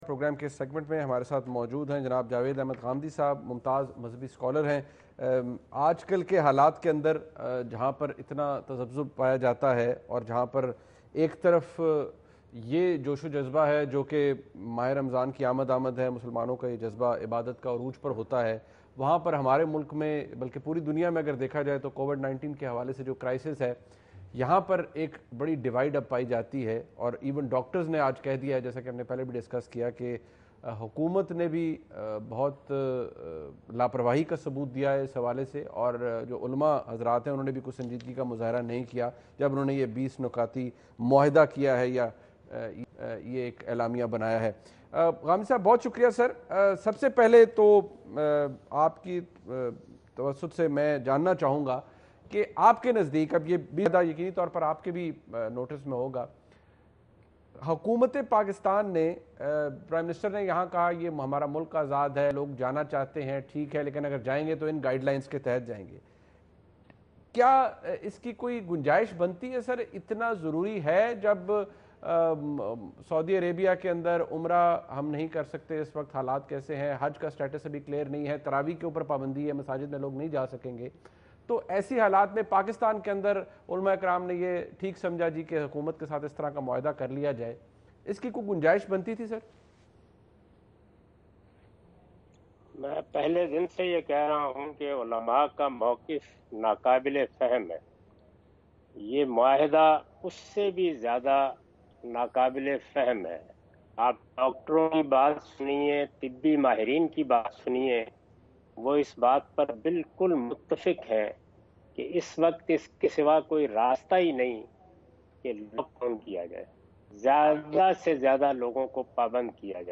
Javed Ahmad Ghamidi's interview with Muneeb Farooq on Geo new Tv’s program “Appas Ki Baat. ”